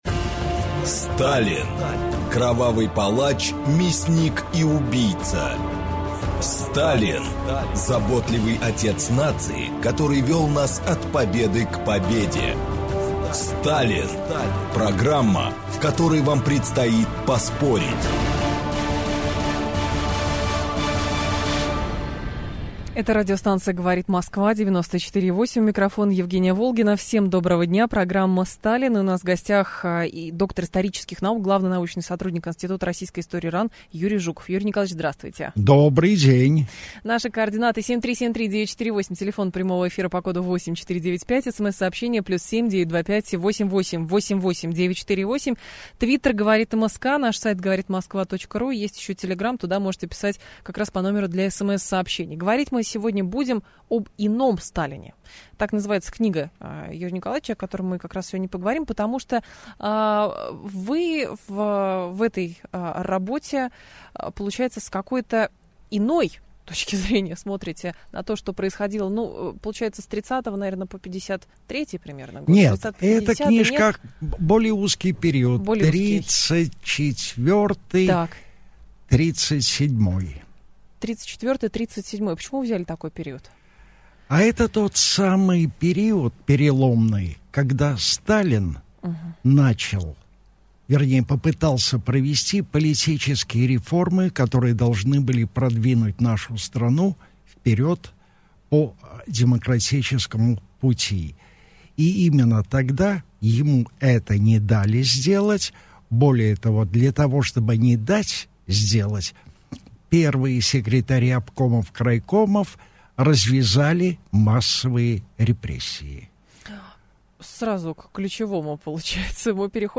Аудиокнига Иной Сталин | Библиотека аудиокниг